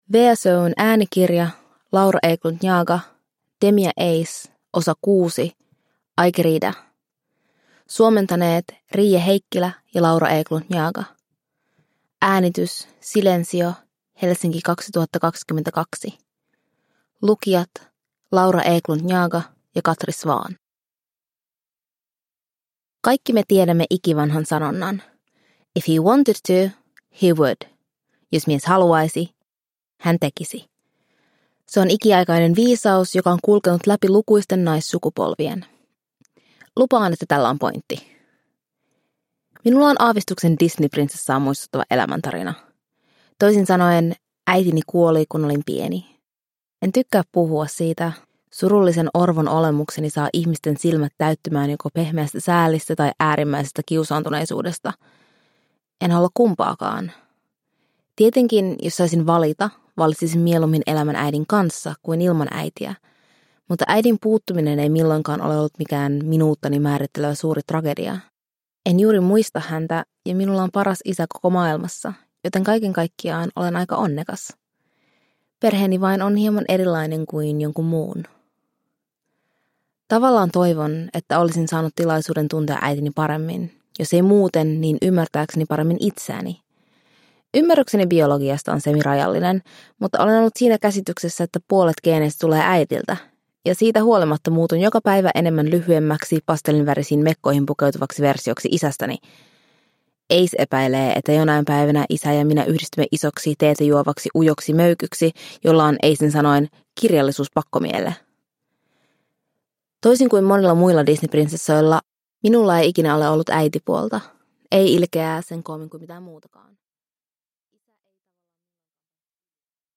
Demi & Ace 6: Ai querida – Ljudbok – Laddas ner